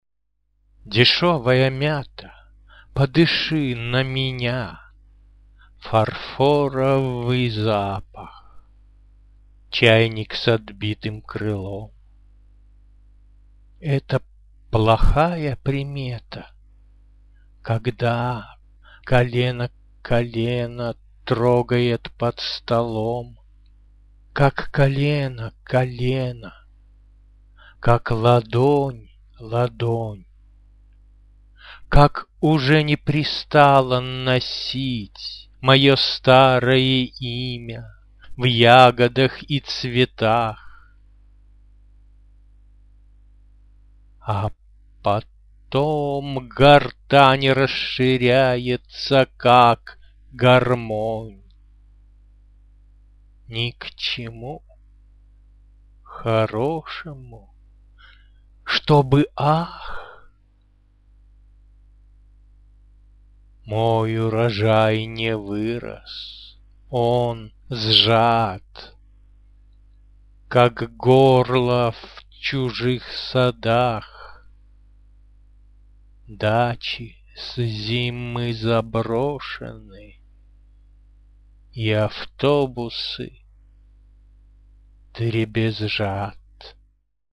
звучащие стихи